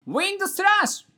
呪文 魔法 ボイス 声素材 – Magic Spell Voice